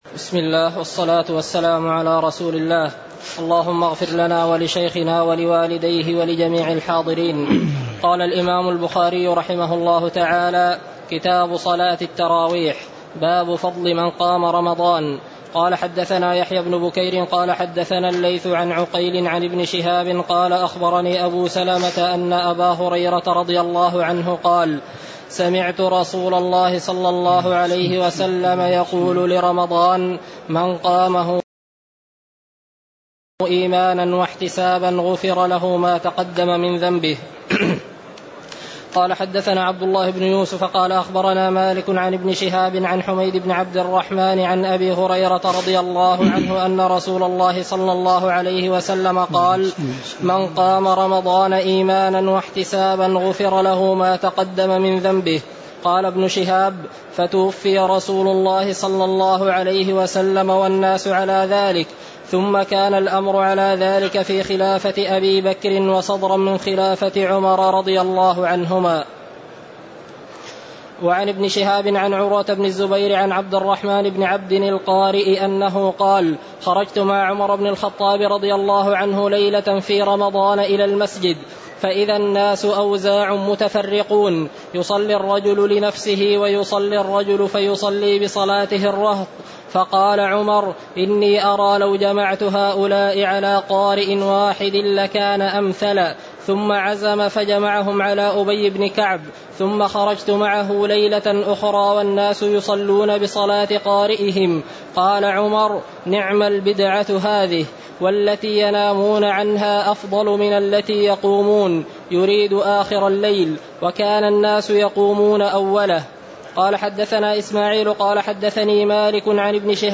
تاريخ النشر ١٧ رمضان ١٤٣٨ هـ المكان: المسجد النبوي الشيخ